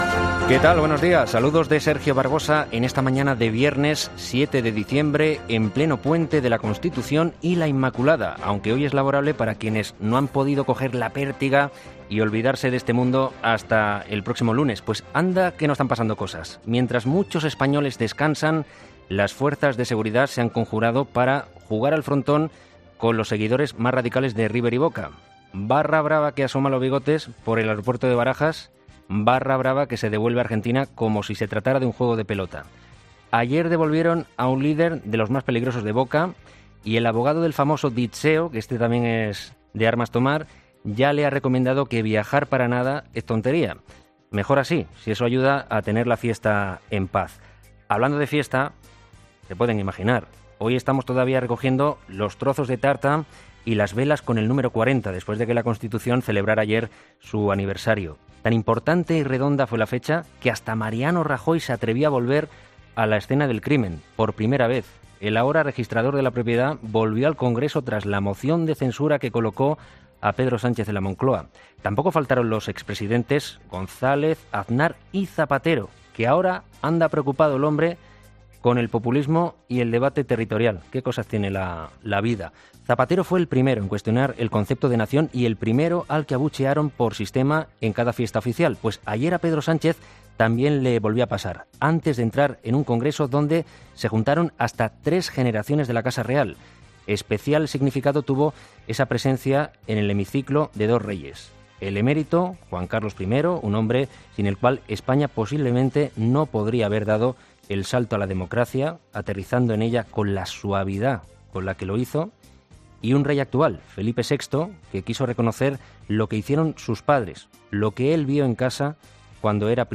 Monólogo de las 8 de Herrera